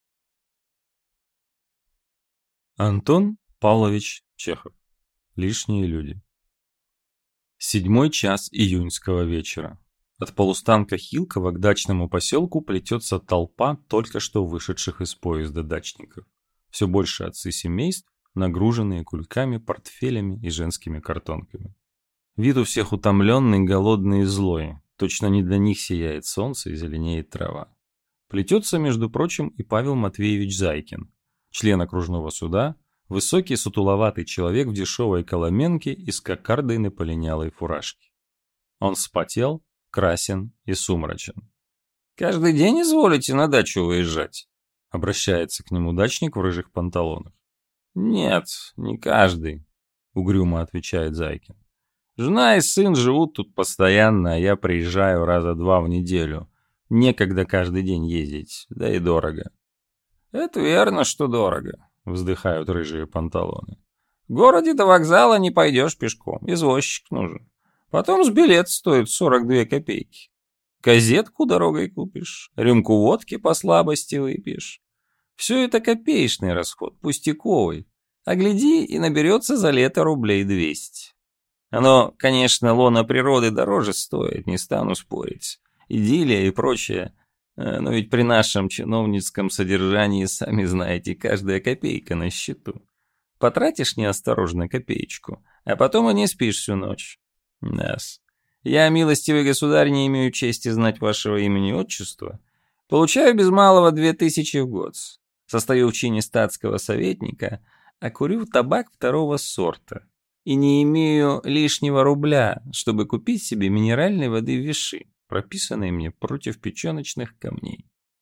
Аудиокнига Лишние люди | Библиотека аудиокниг